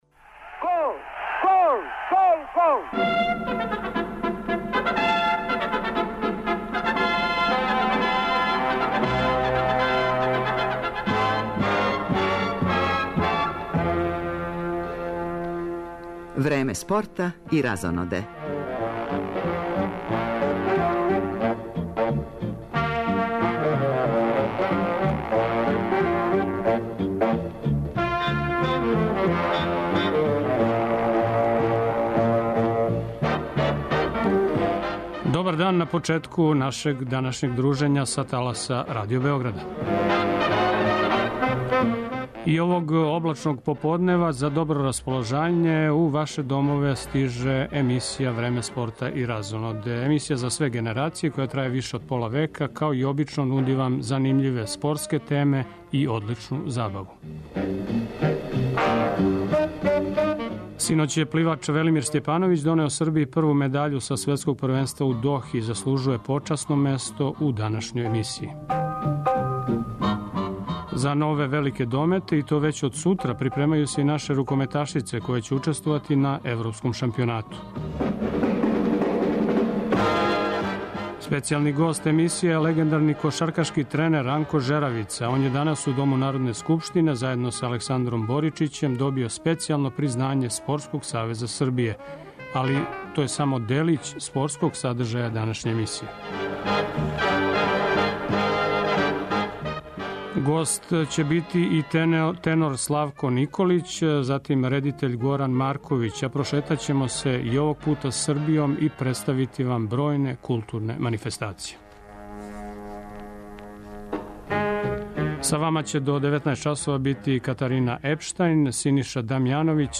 Гост емисије је прослављени кошаркашки тренер Ранко Жеравица, један од најбољих светских тренера и члан Куће славних у Мадриду. Ранко Жеравица је добио награду Спортског савеза Србије за животно дело.